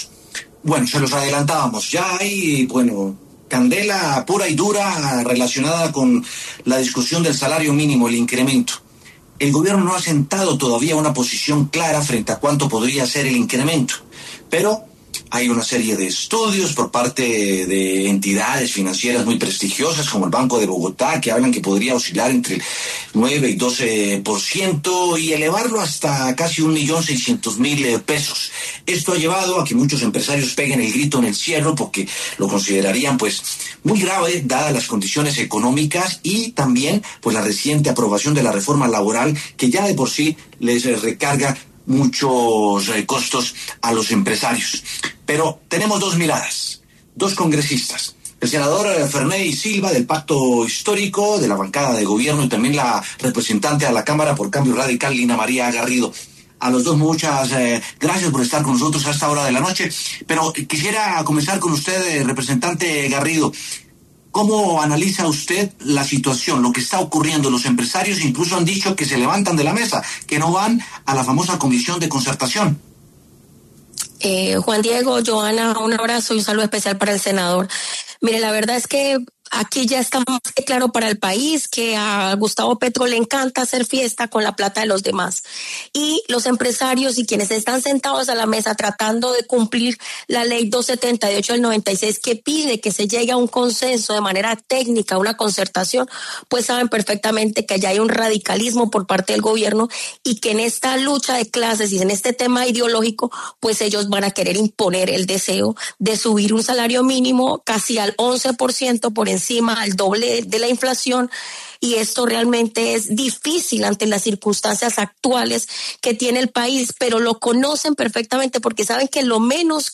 Los congresistas Lina María Garrido, de Cambio Radical, y Ferney Silva, del Pacto Histórico, debatieron en W Sin Carreta el posible aumento del salario mínimo 2026.